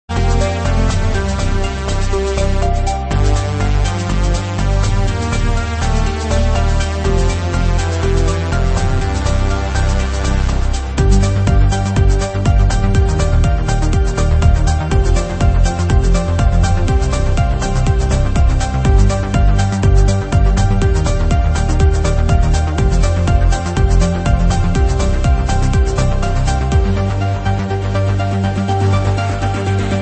Your one-stop site for Commodore 64 SID chiptune remixes.